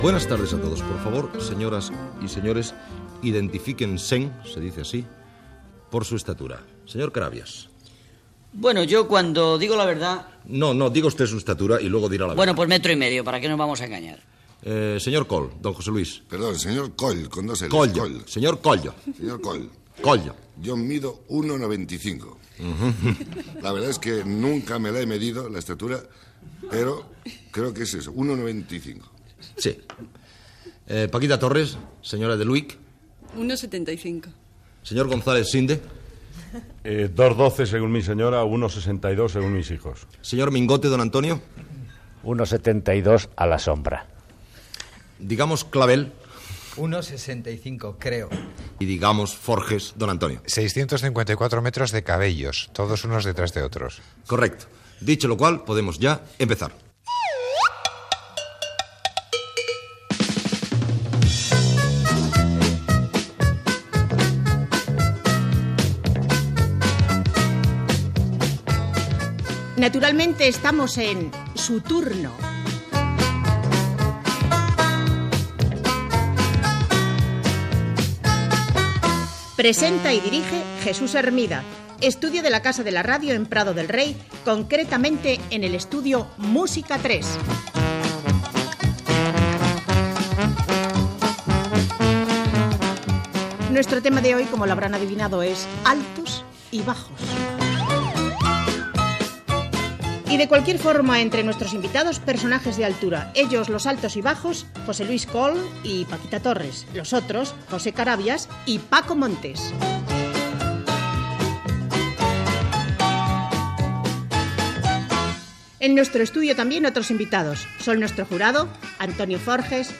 Presentació dels invitats que donen la seva alçada, identificació i tema del programa "Altos y bajos".
Entreteniment